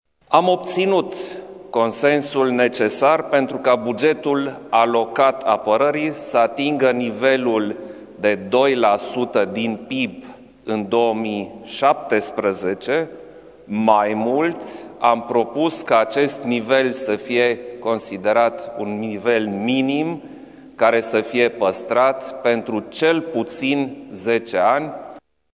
Aşa a afirmat, în urmă cu puţin timp, presedintele Klaus Iohannis, după consultările avute la Palatul Cotroceni cu reprezentanţii partidelor politice parlamentare.
Preşedintele Klaus Iohannis: